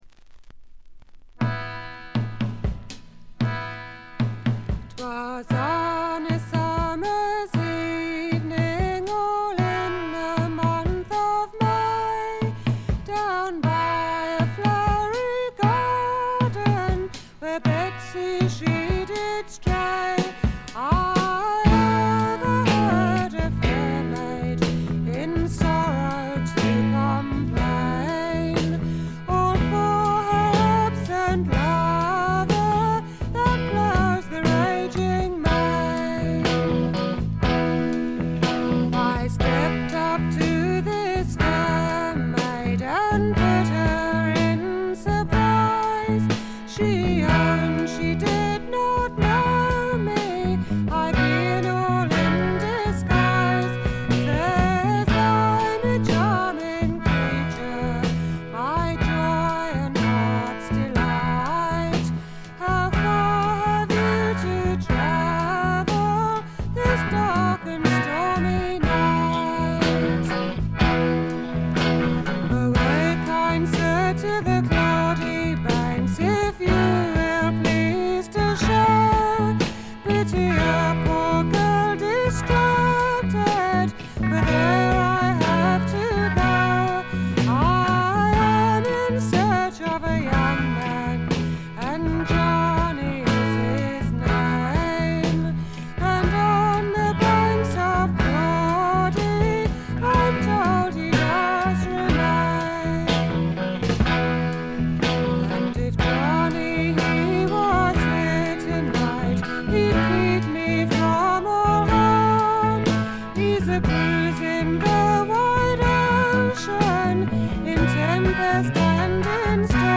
ごくわずかなノイズ感のみ。
エレクトリック・トラッド最高峰の一枚。
試聴曲は現品からの取り込み音源です。